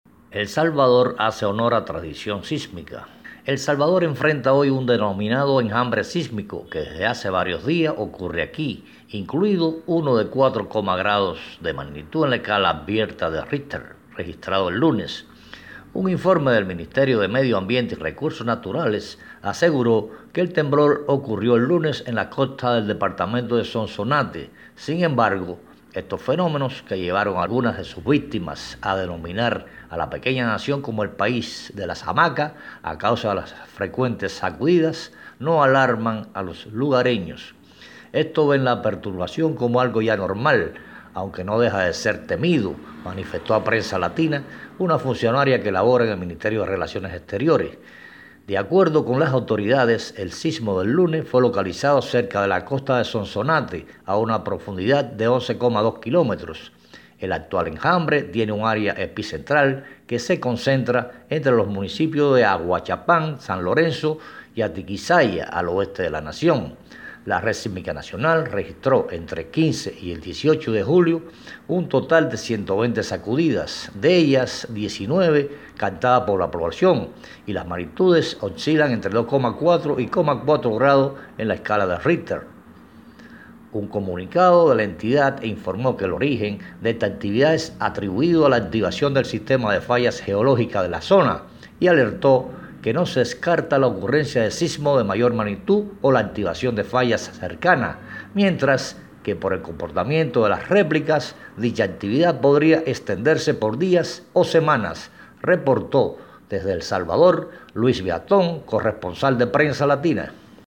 desde San Salvador